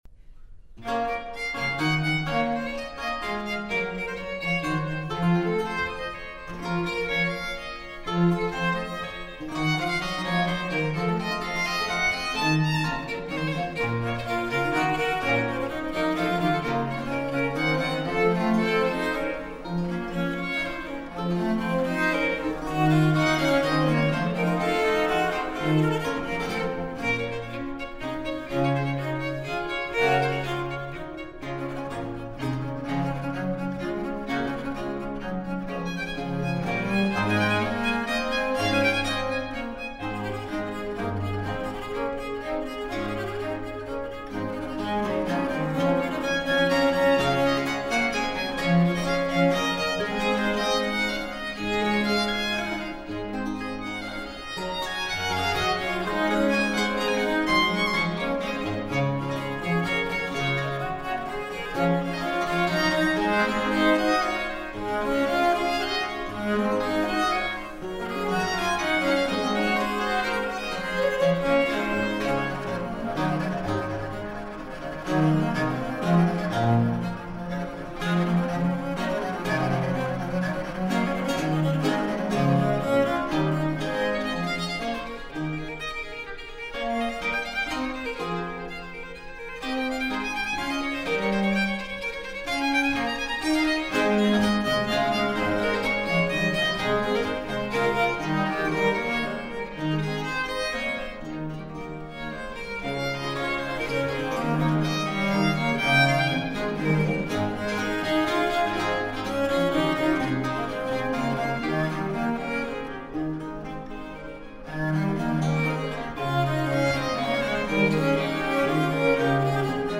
Viola da gamba
音樂類型：古典音樂
再加上細膩而殘響豐富的錄音